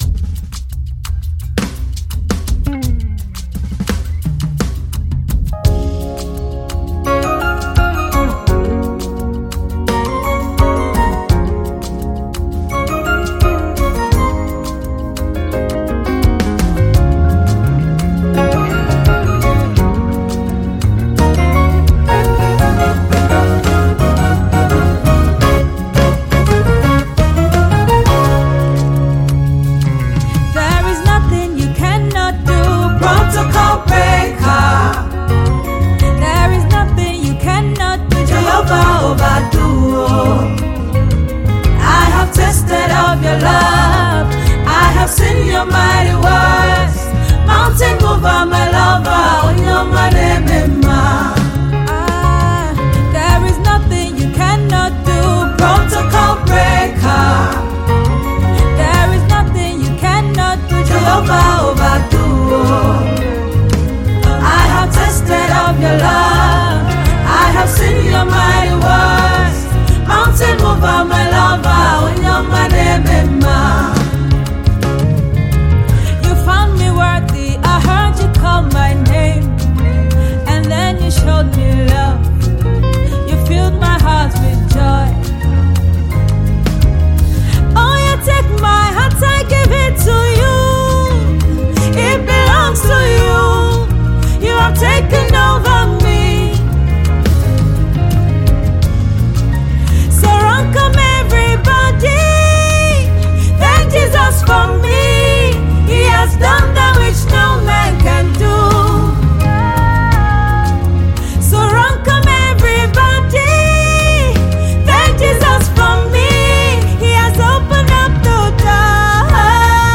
Nigerian singer
gospel